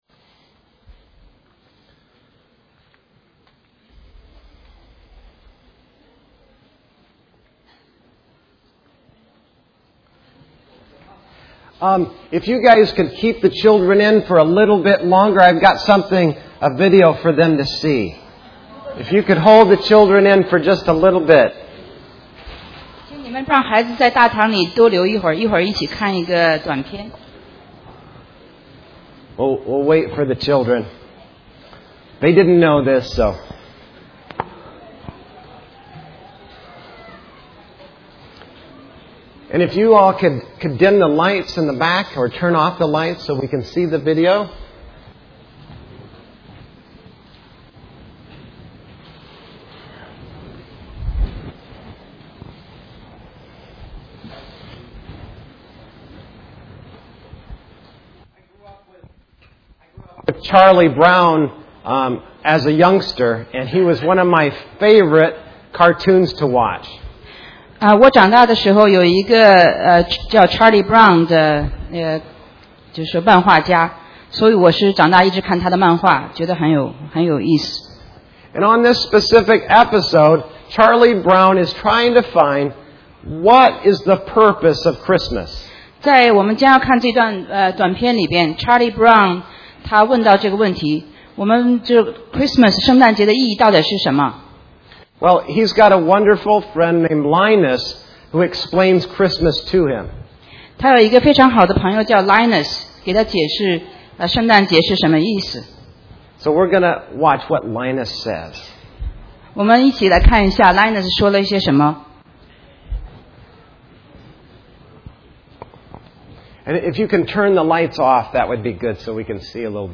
中文讲道